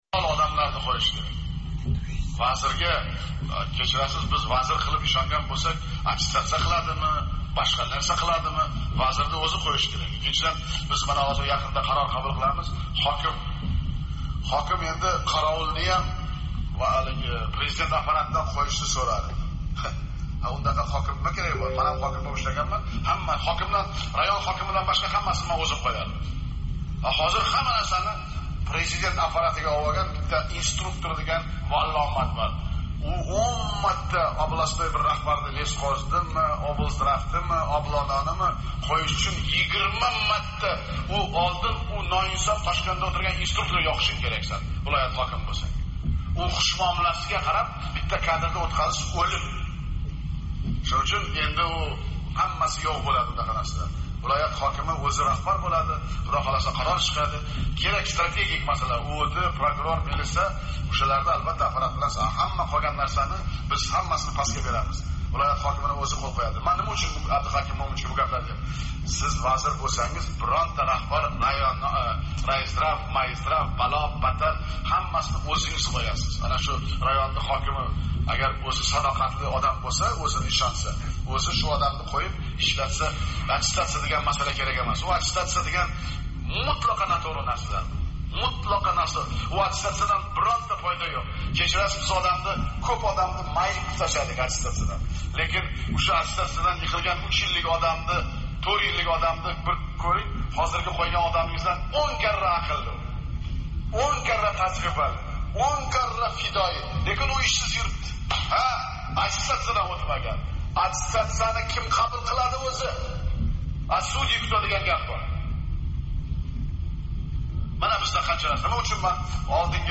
Шавкат Мирзиёевнинг овози акс этган бу аудио ёзилган селектор йиғилиши қачон ва қай мавзуда ўтказилгани Озодликка маълум эмас.